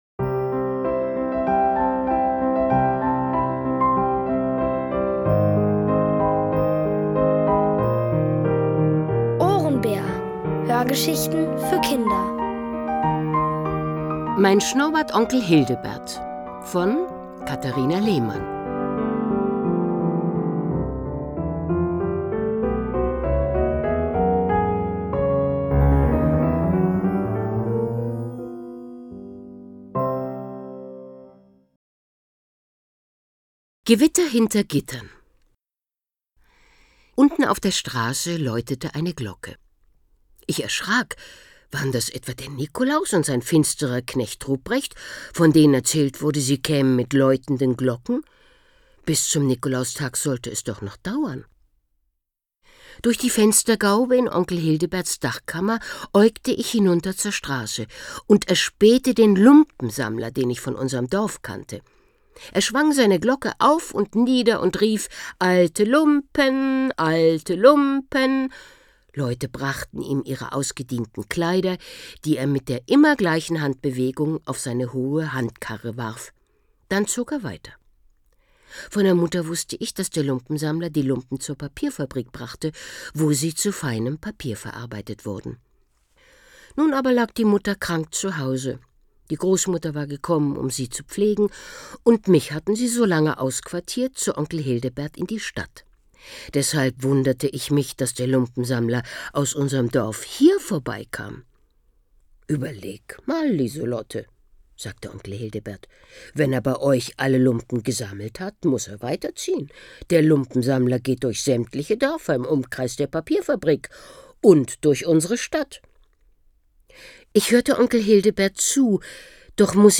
Von Autoren extra für die Reihe geschrieben und von bekannten Schauspielern gelesen.
Es liest: Uta Hallant.